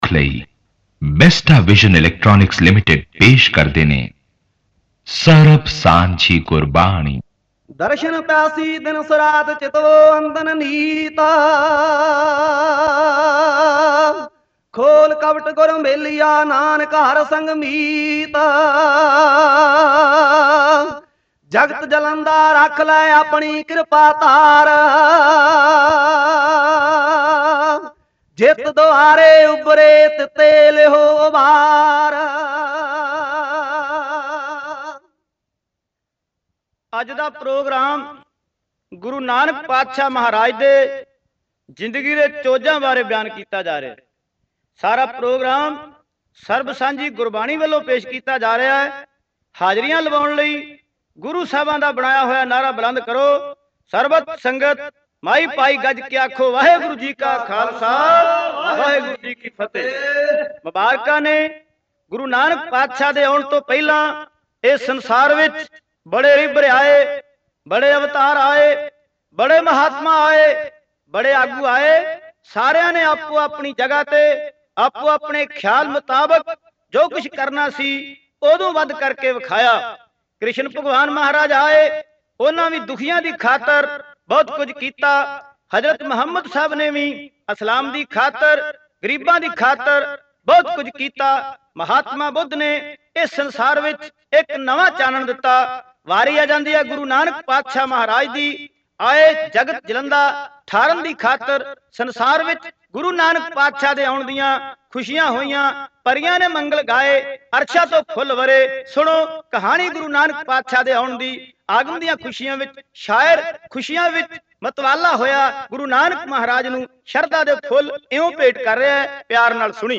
Genre: Kavishri